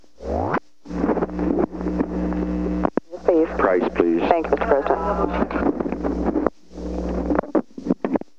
Recording Device: White House Telephone
On January 10, 1972, President Richard M. Nixon and the White House operator talked on the telephone at an unknown time between 3:42 pm and 3:56 pm. The White House Telephone taping system captured this recording, which is known as Conversation 018-043 of the White House Tapes.
The President talked with the White House operator.